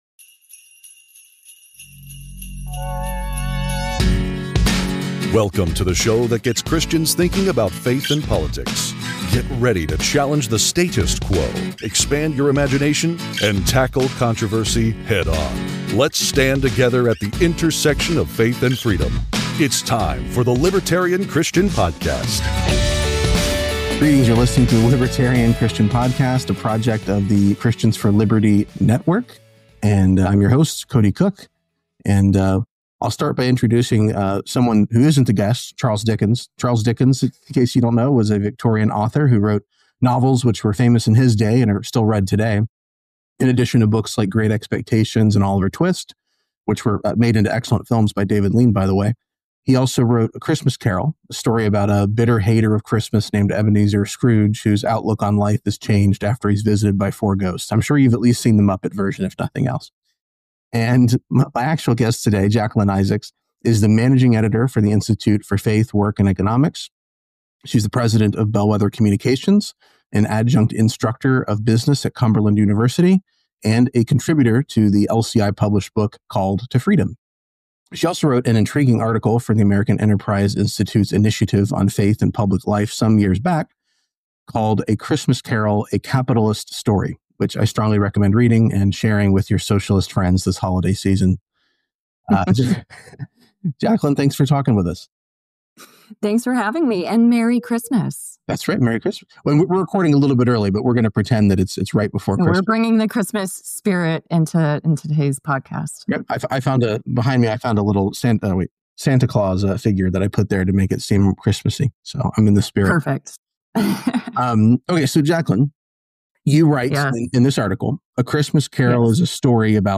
Don’t get humbugged by socialist talking points–listen to this compelling conversation or else the Ghost of Christmases Libertarian might just come to pay you a visit tonight.